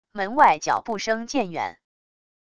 门外脚步声渐远wav音频